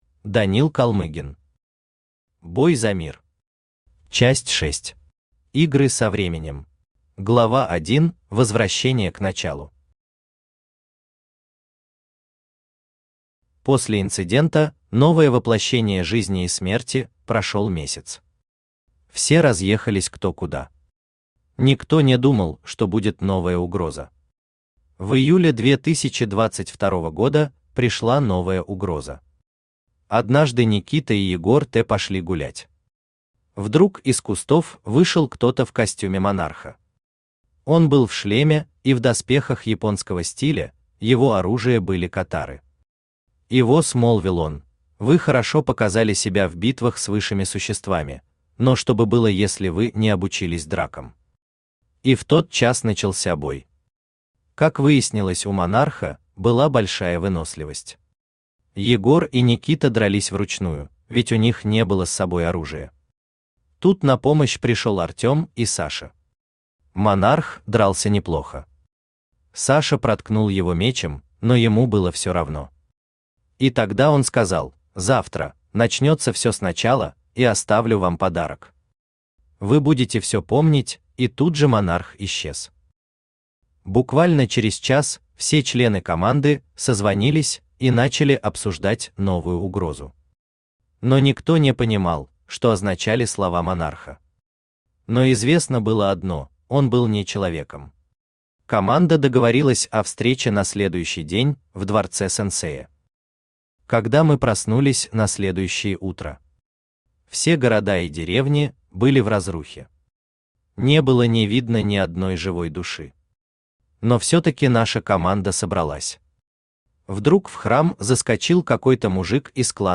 Аудиокнига Бой за мир. Часть 6. Игры со временем | Библиотека аудиокниг